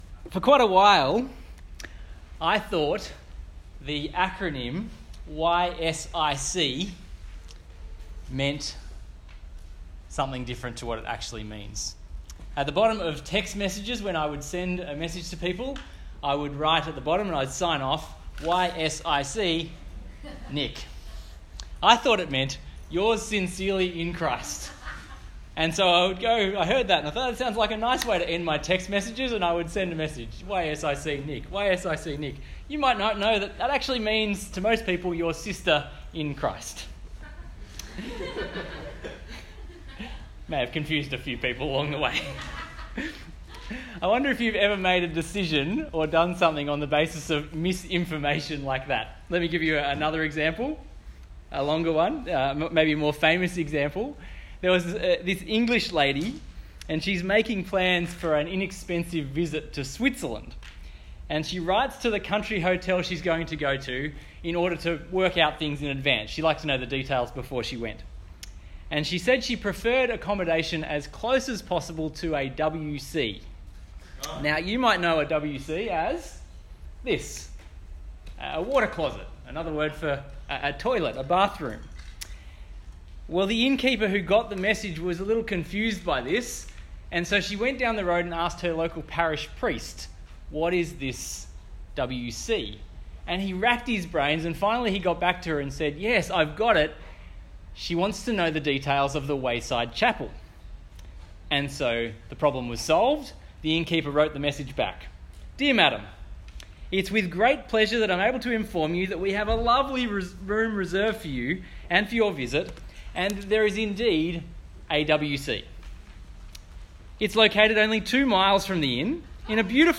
Talk Type: Bible Talk